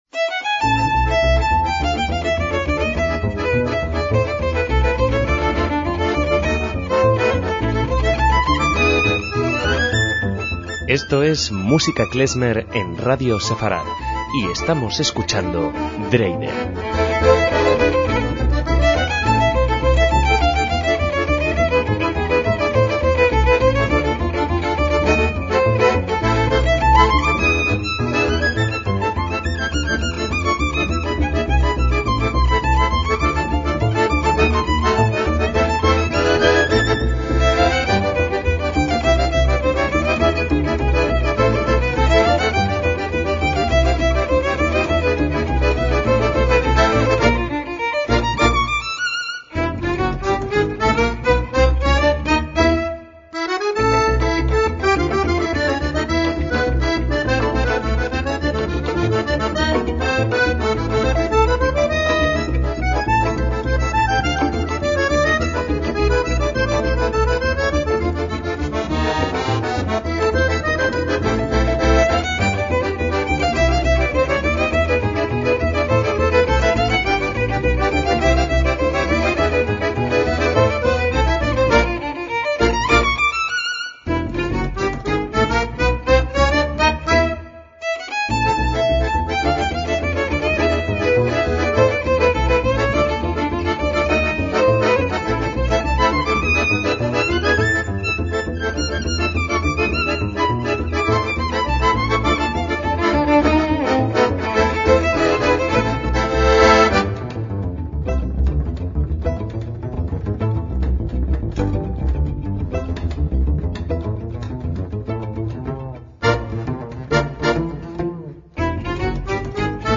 MÚSICA KLEZMER
trío italiano de música klezmer
violín
contrabajo
acordeón
clarinete